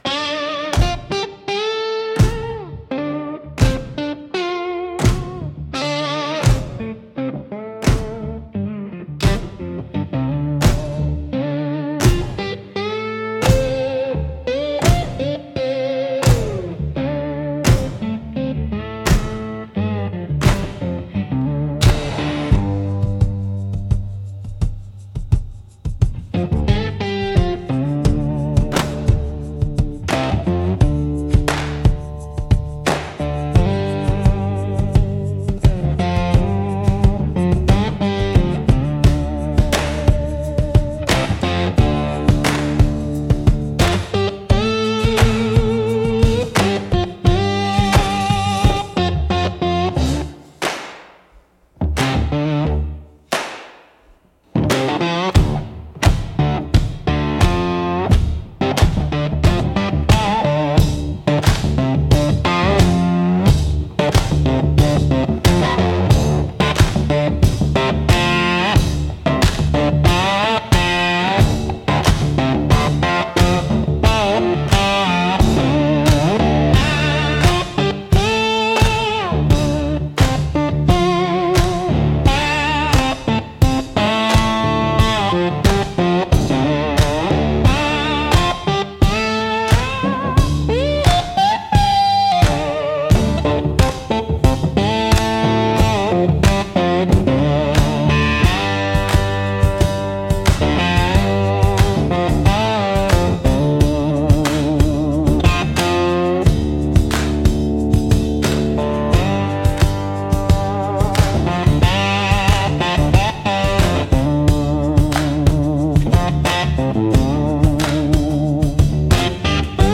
Instrumental - Copperwire Shuffle 3.24